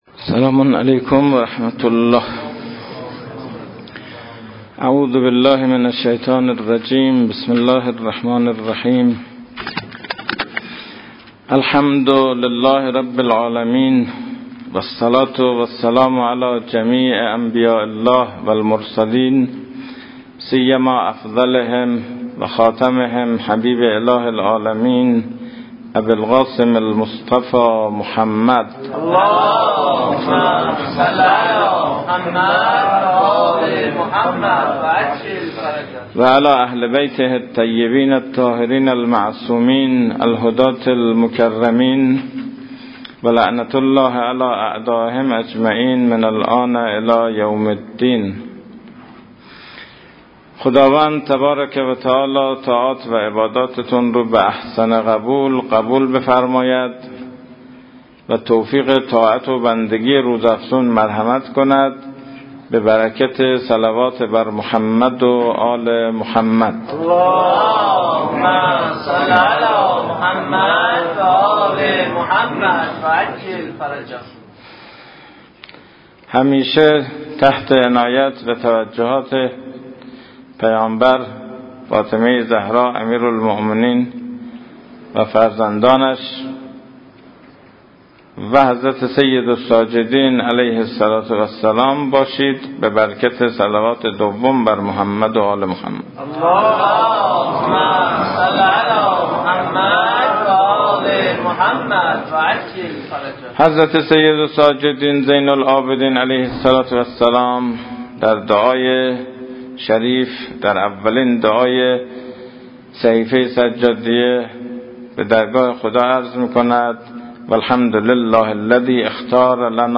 این مباحث در ماه مبارک رمضان سال 1397 بیان شده است.